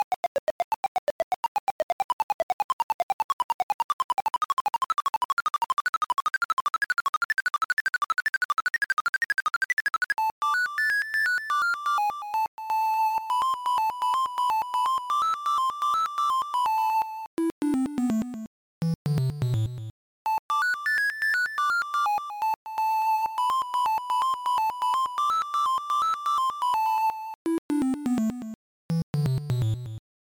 Unused music